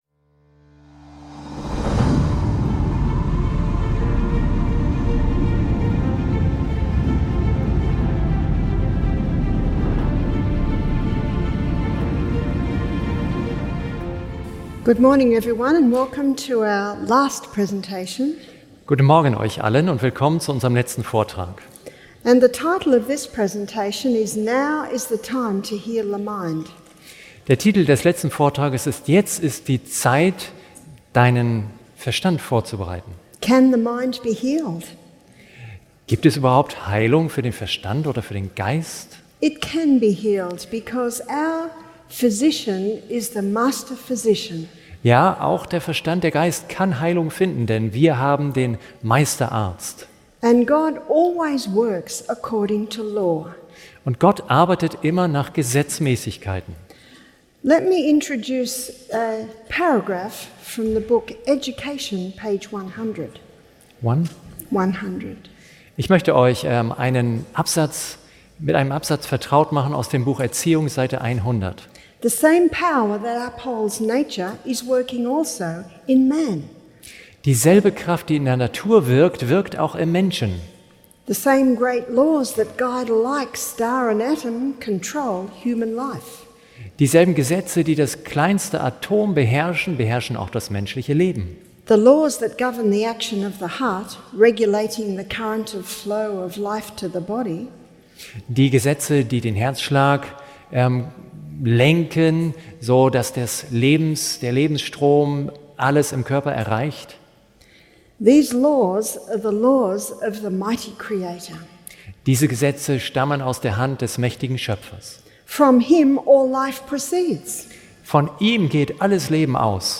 In diesem faszinierenden Vortrag wird erklärt, wie Geist und Körper durch die Einhaltung göttlicher Gesetze geheilt werden können.